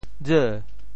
尔（爾） 部首拼音 部首 小 总笔划 5 部外笔划 2 普通话 ěr 潮州发音 潮州 re2 文 潮阳 ru2 文 澄海 re2 文 揭阳 re2 文 饶平 re2 文 汕头 re2 文 中文解释 潮州 re2 文 对应普通话: ěr ①你，你的：～父 | ～辈 | ～汝（你我相称，关系密切） | ～曹（你们这些人） | ～虞我诈。